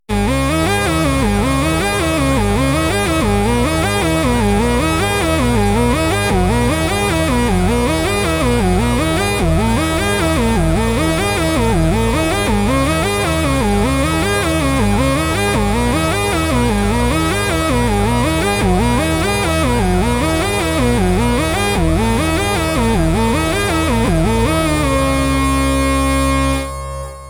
4声の美しいハーモニーとリズミカルなディレイを生み出す、直感的なピッチシフター
Quadravox | Synth | Preset: Lead Inflator
Synth-1-Lead-Inflator.mp3